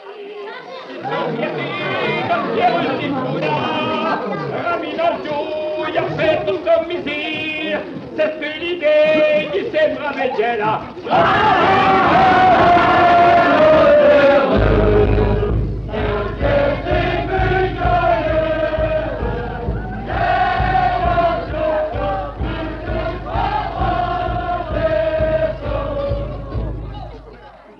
Découvrez notre collection d'enregistrements de musique traditionnelle de Wallonie
Chant de quête
Type : chanson de quête Aire culturelle d'origine : Hesbaye liégeoise Interprète(s) : Enfants de Vyle-et-Tharoul Date d'enregistrement : 1955